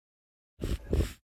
Minecraft Version Minecraft Version latest Latest Release | Latest Snapshot latest / assets / minecraft / sounds / mob / sniffer / scenting3.ogg Compare With Compare With Latest Release | Latest Snapshot
scenting3.ogg